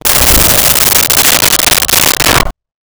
Creature Snarl 02
Creature Snarl 02.wav